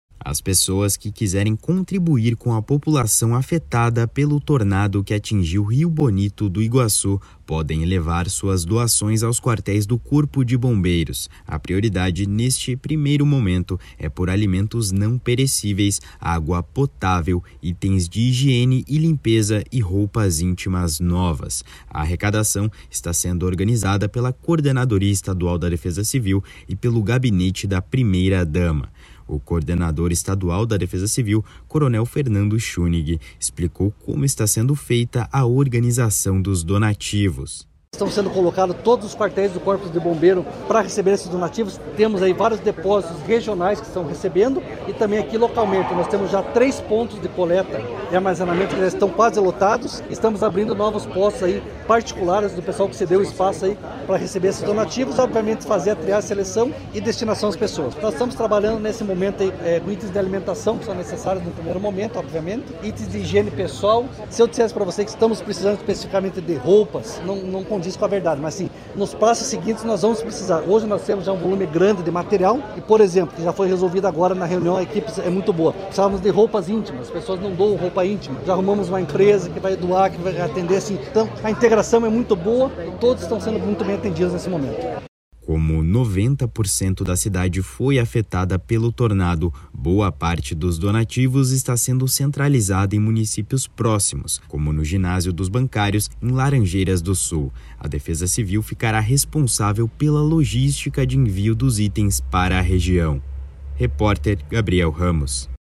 O coordenador estadual da Defesa Civil, coronel Fernando Schunig, explicou como está sendo feita a organização dos donativos.